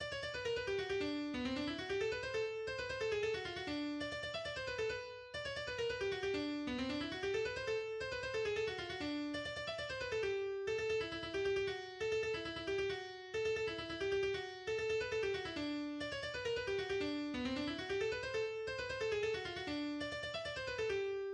Jarabe tapatío est le titre d'un morceau de musique instrumentale folklorique et de la danse qui l'accompagne, alternant en introduction le rythme rapide zapateado avec ensuite celui de valse puis de polka.
La ville de Guadalajara a donné naissance au Jarabe tapatio qui est l'une des danses nationales du Mexique, jouée le plus souvent par des mariachis[1].